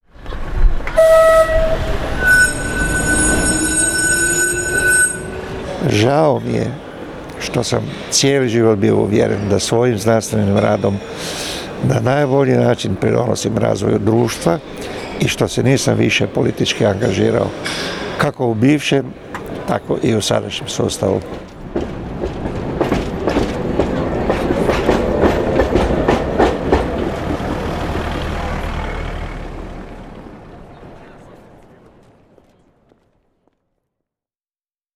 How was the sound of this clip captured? STATEMENTS BROADCASTED ON RADIO "SLJEME":